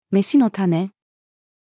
飯の種-female.mp3